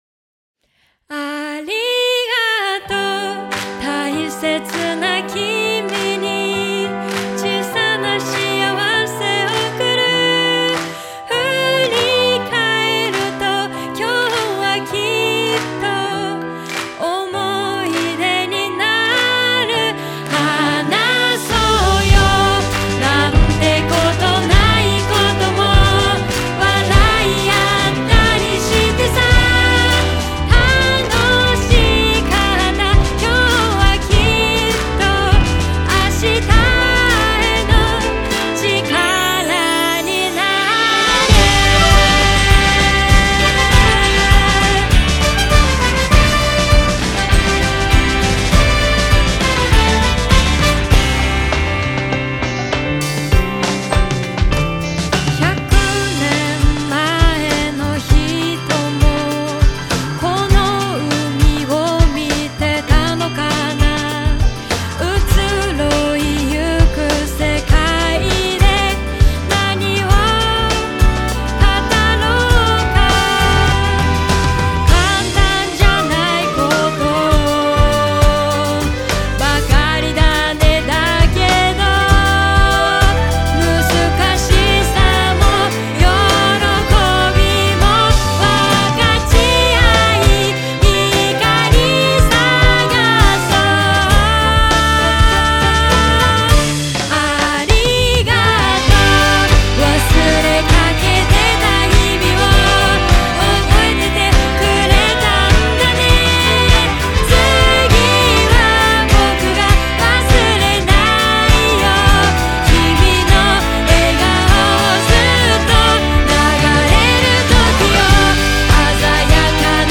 SONG
ROCK / POPS
既存の社歌のイメージを覆すような軽快な曲調と普遍的な歌詞で、真の意味で老若男女に愛されるような楽曲を目指しました。